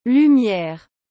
Como se pronuncia lumière corretamente?
O som fica algo como “lü-miérr”, com o “u” sendo bem arredondado e o “r” final mais suave do que em português.